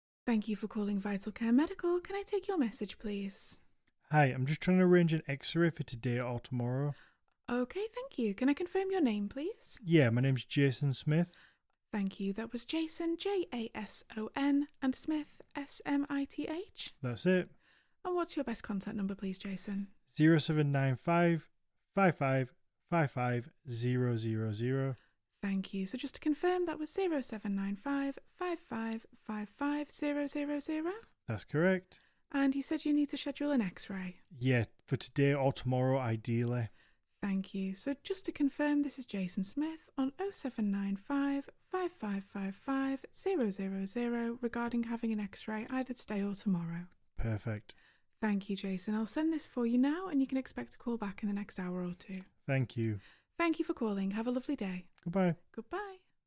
• UK receptionists
phone-message-answering-service-call-sample-MessageExpress.mp3